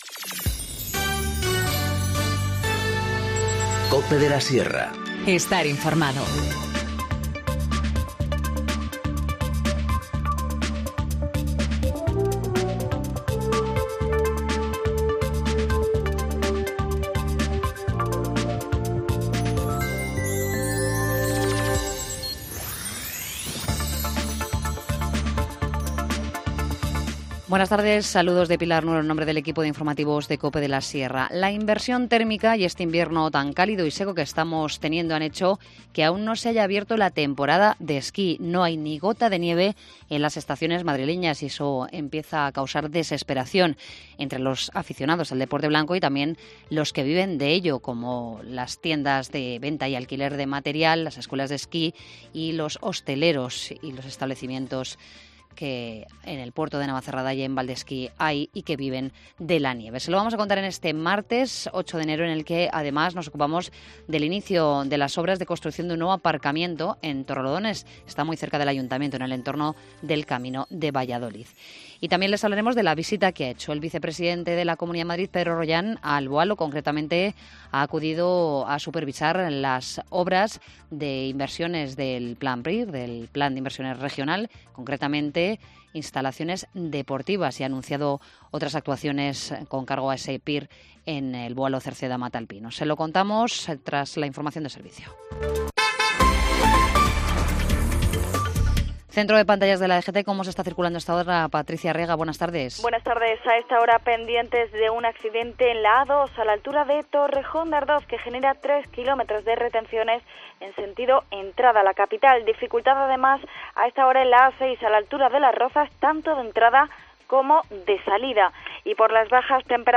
Informativo Mediodía 8 enero- 14:20h